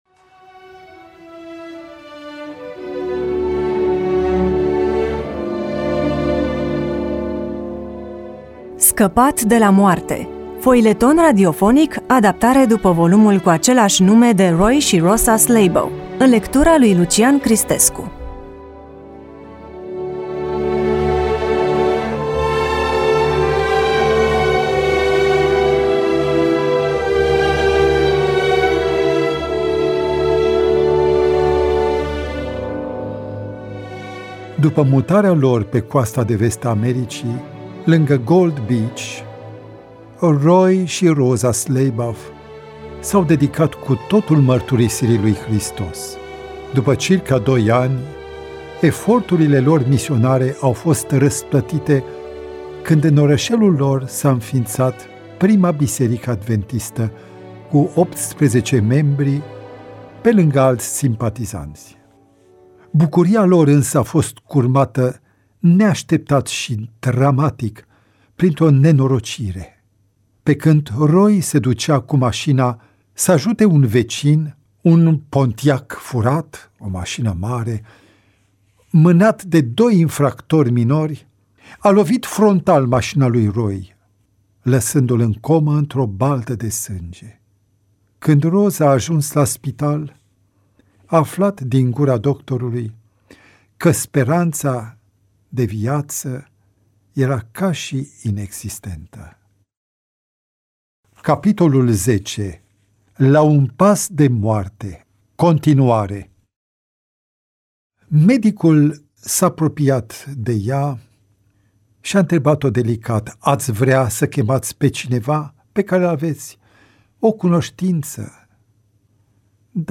EMISIUNEA: Roman foileton DATA INREGISTRARII: 07.02.2025 VIZUALIZARI: 85